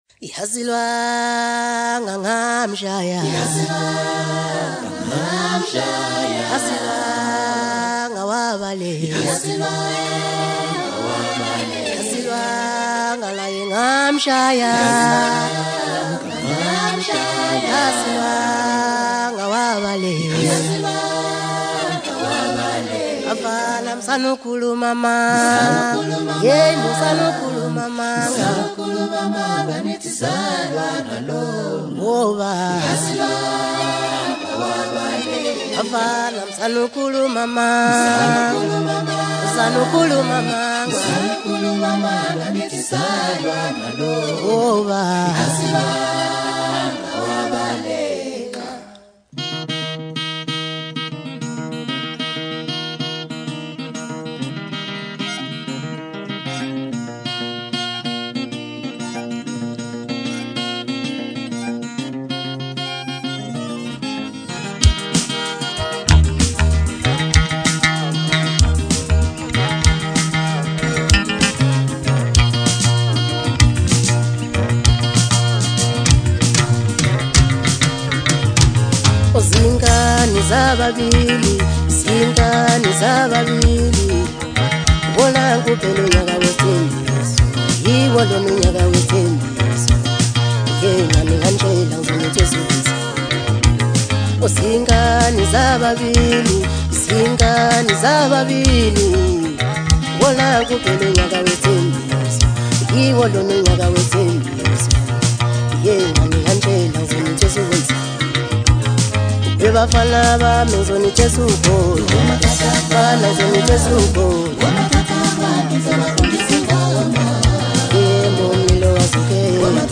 Home » DJ Mix » Hip Hop » Maskandi
Gifted vocalist